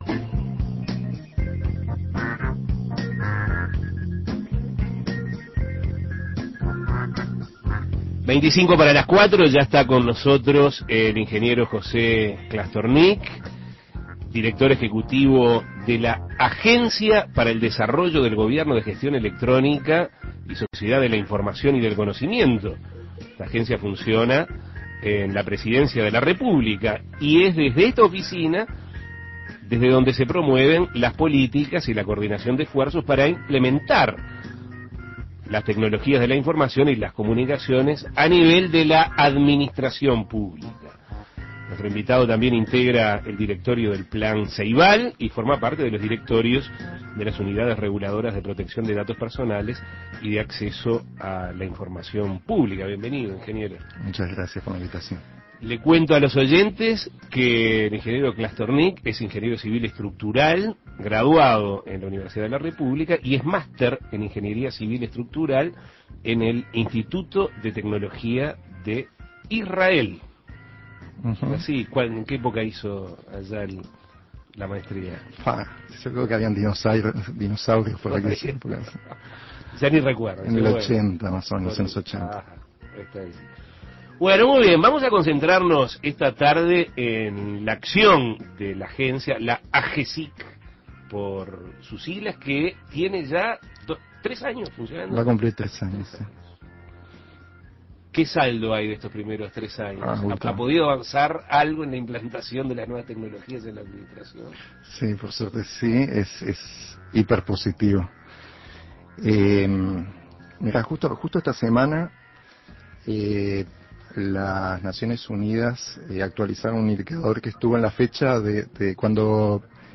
Escuche la entrevista con el ingeniero José Clastornik.